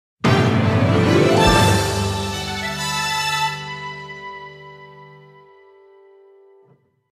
The sound that plays when a new tour opens